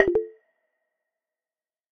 menu-back-click.ogg